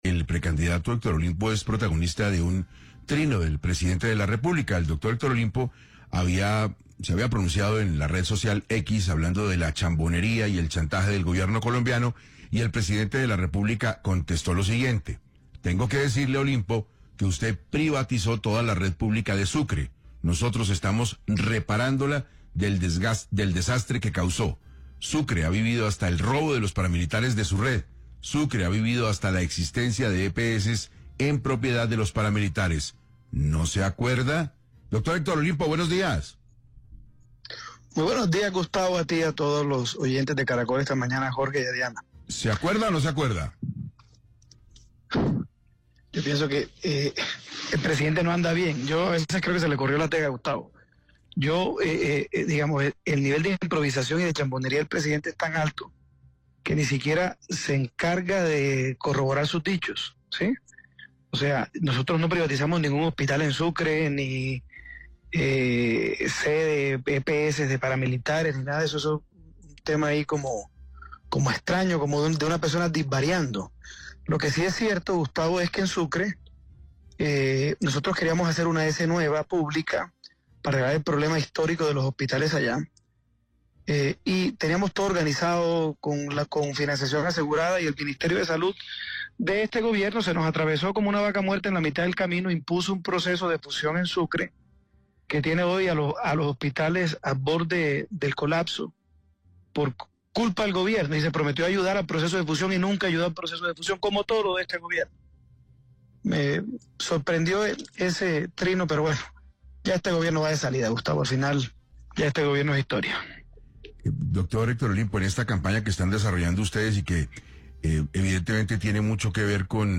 En entrevista con 6AM de Caracol Radio, Olimpo desmintió de manera tajante esas acusaciones.